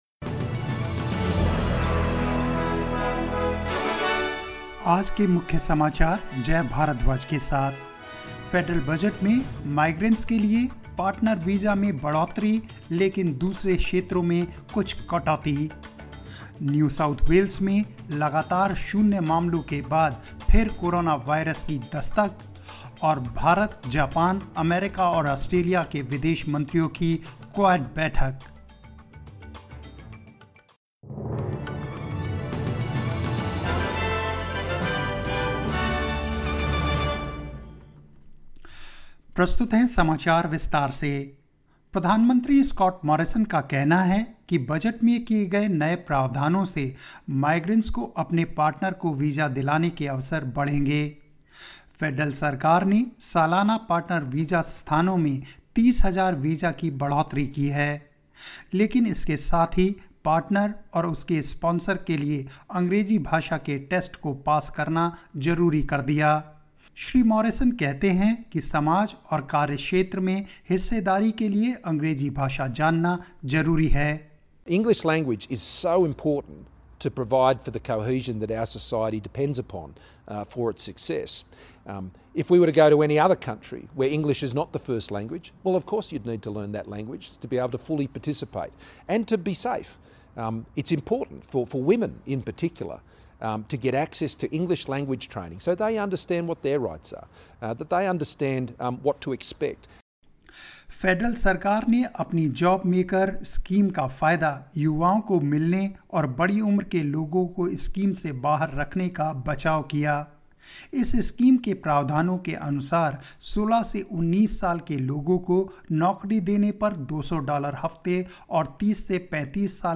News in Hindi 7 October 2020